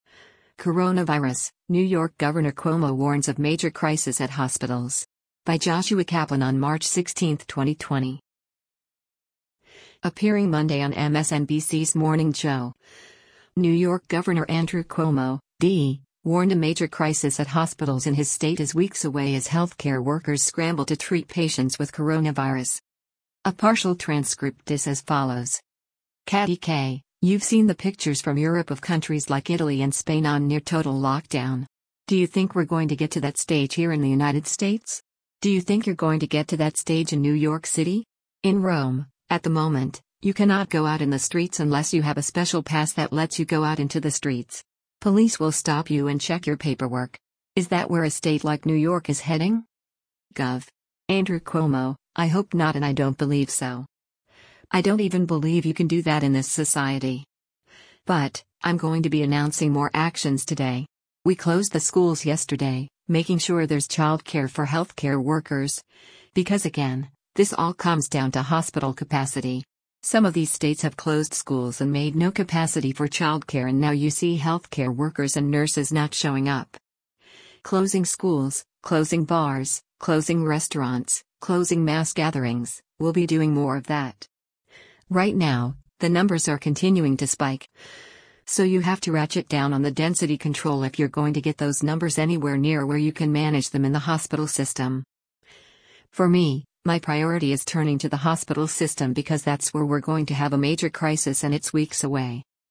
Appearing Monday on MSNBC’s Morning Joe, New York Gov. Andrew Cuomo (D) warned a “major crisis” at hospitals in his state is weeks away as healthcare workers scramble to treat patients with coronavirus.